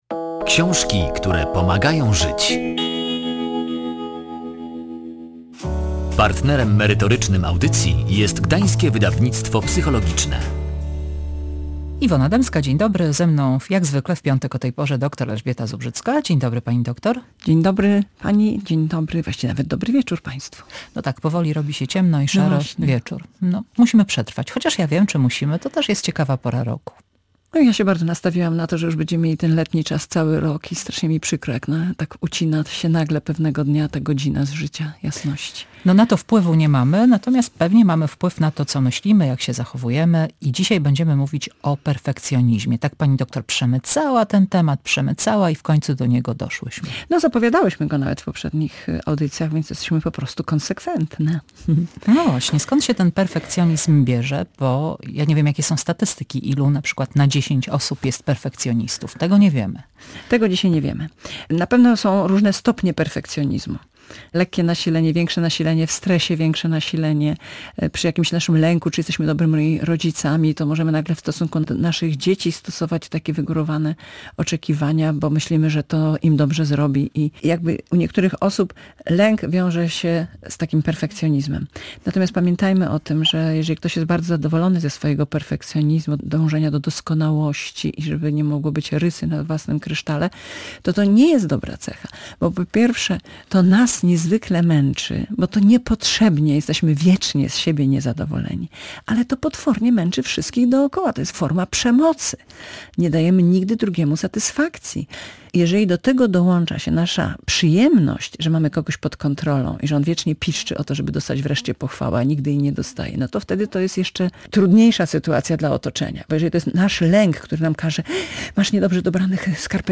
Perfekcjonizm nie ułatwia życia – rozmowa z psycholog